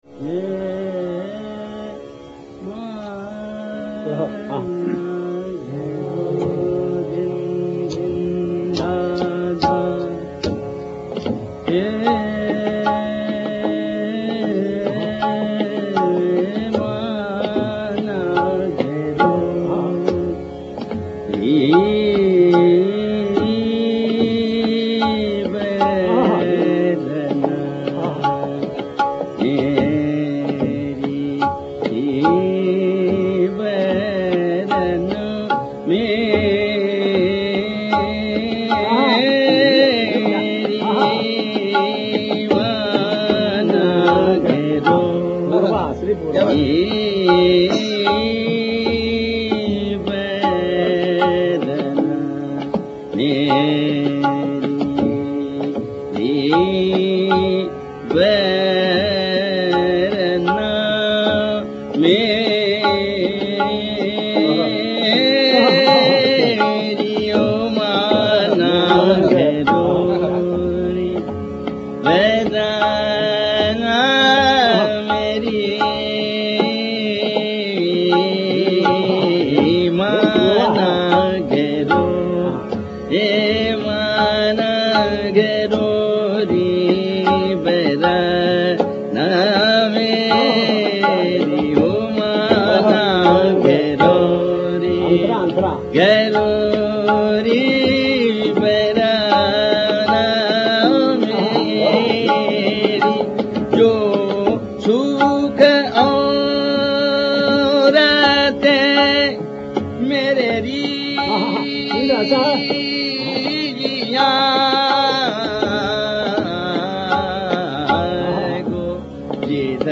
S M, M G P and m, m P, P d, d N d P